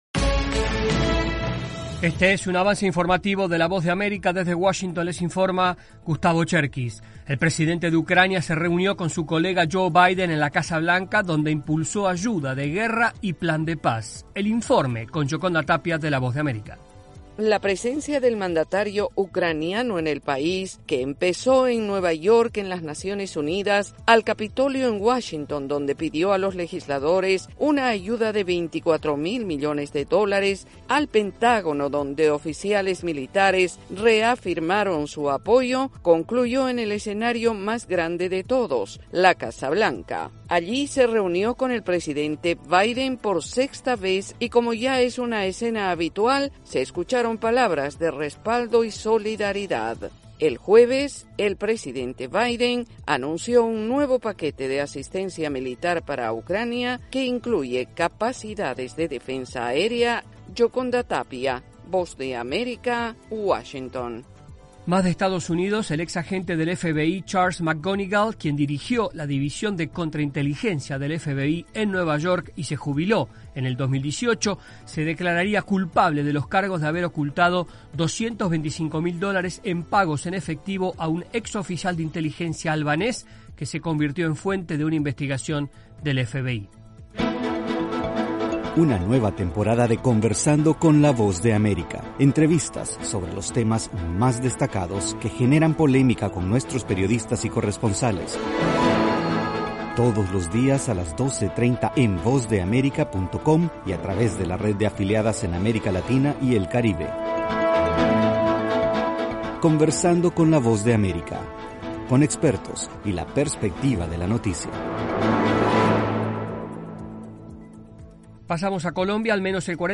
Avance Informativo 9:00AM
Este es un avance informativo de la Voz de América.